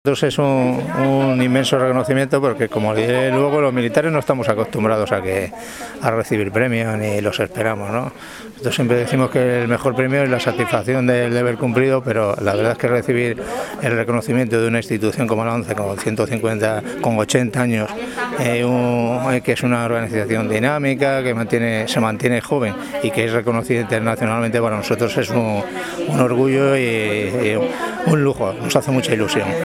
El reconocimiento a la fuerza y la solidaridad de la sociedad a través de la concesión de los Premios Solidarios ONCE 2021Abre Web externa en ventana nueva llegó, el pasado 15 de diciembre, a la Comunidad de Madrid, con la celebración de su ceremonia de entrega de galardones, que tuvo lugar en el Complejo Deportivo y Cultura de la ONCE, sito en el Paseo de La Habana, nº 208, de Madrid.